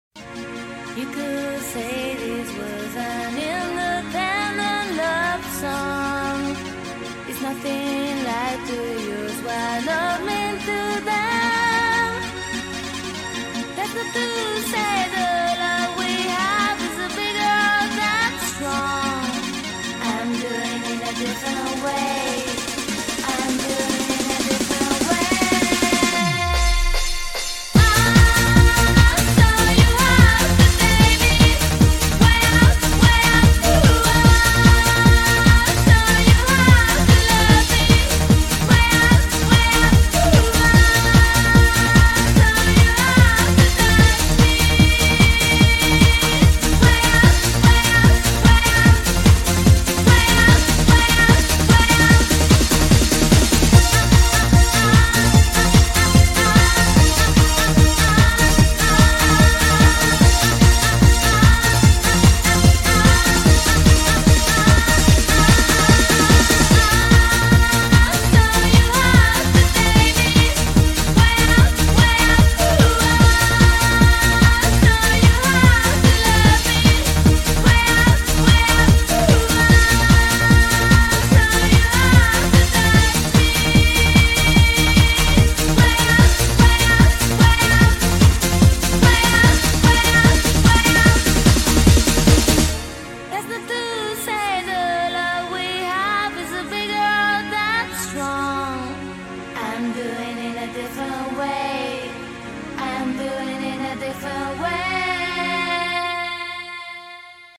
BPM148
Audio QualityPerfect (High Quality)
Comentarios* The real BPM of this song is 147.9